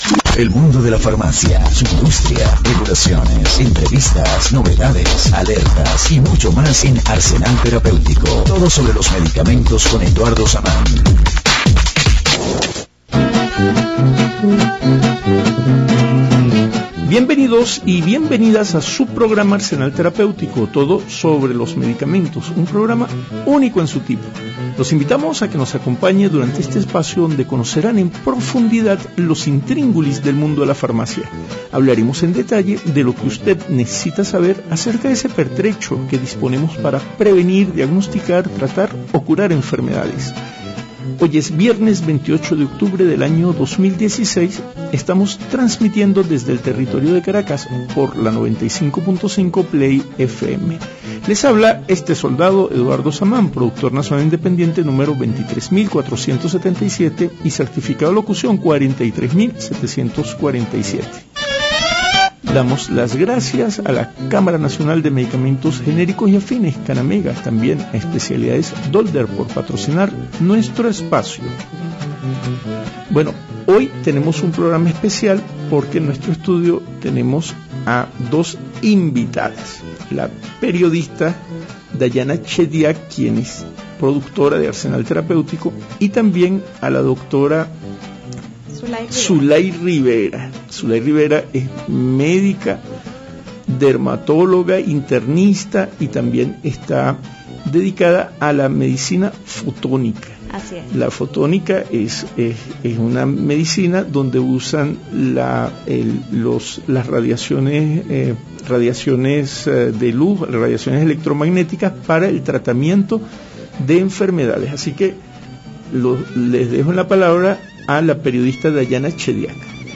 transmitido por Play 95.5 FM, Caracas.